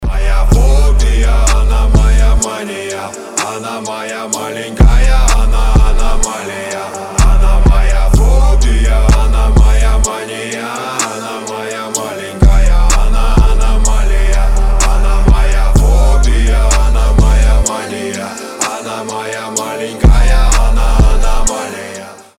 • Качество: 256, Stereo
мужской голос
рэп